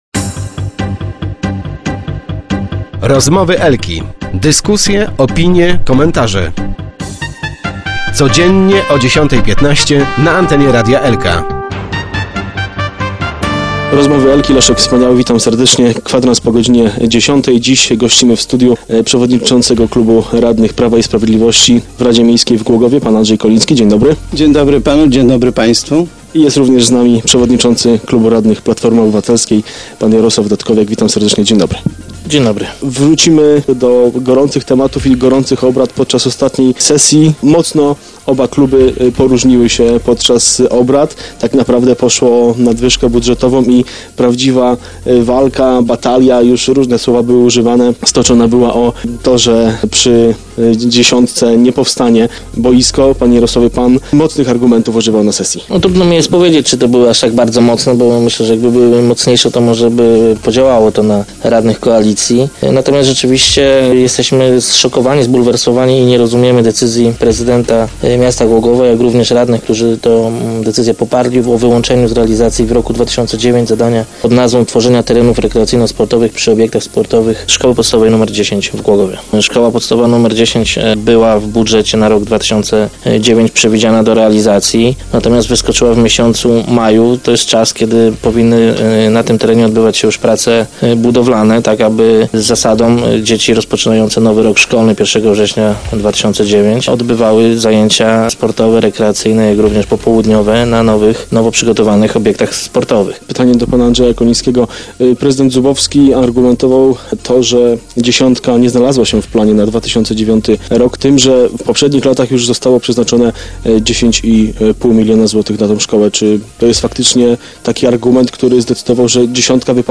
Spór o boisko toczyli radni. W piątek gośćmi Rozmów Elki byli przewodniczący klubu radnych PiS Andrzej Koliński i Jarosław Dudkowiak, szef klubu PO.